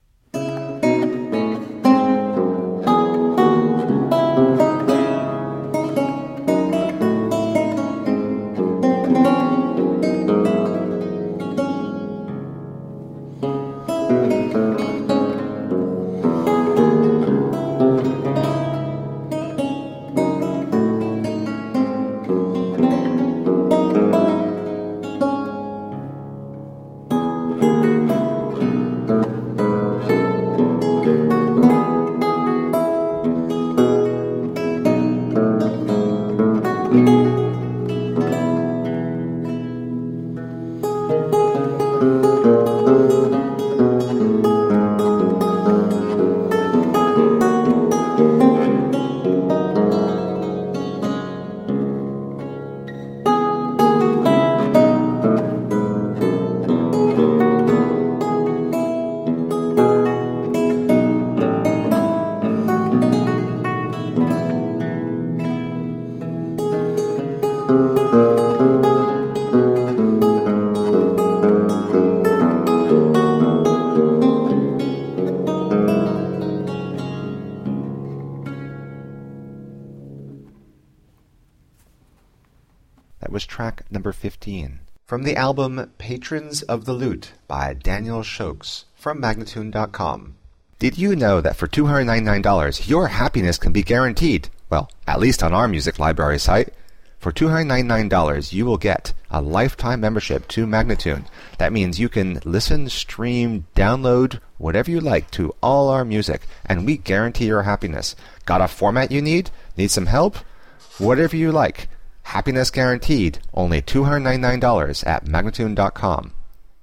A feast of baroque lute.
Classical, Baroque, Instrumental